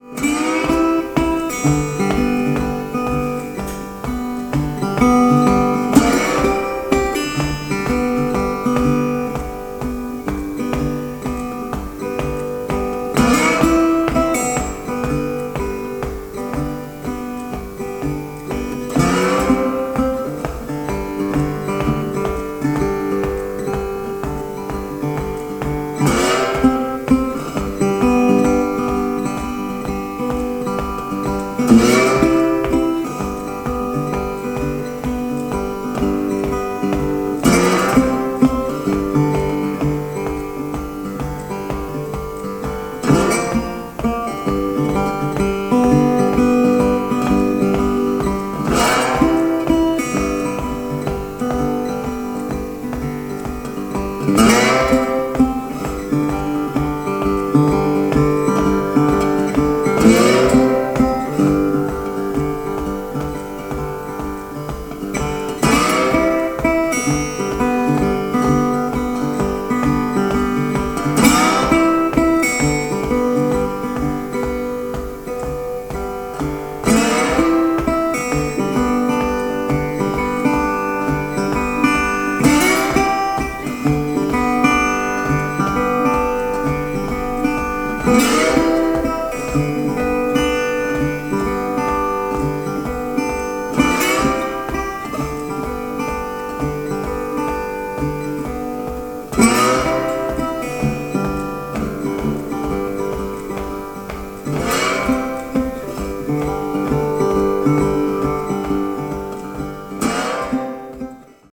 Country & Western Playlist